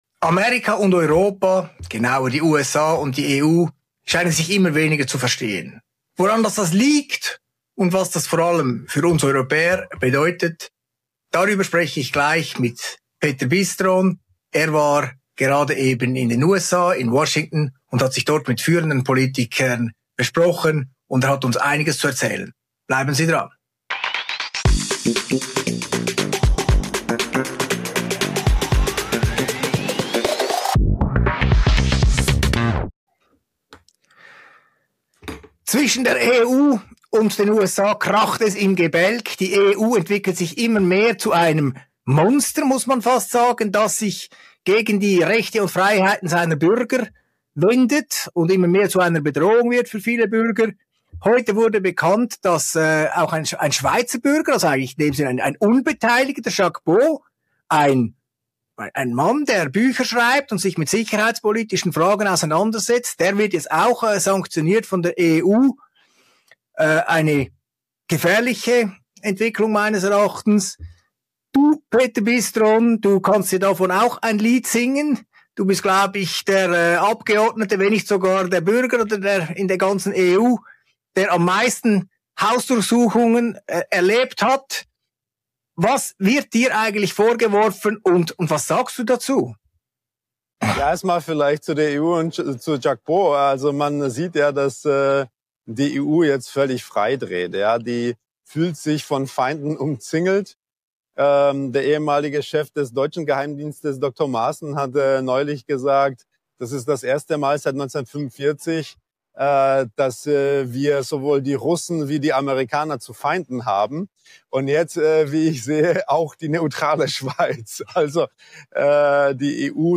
Im Gespräch zwischen Claudio Zanetti und dem EU-Abgeordneten Petr Bystron (AfD) wird die Europäische Union fundamental kritisiert.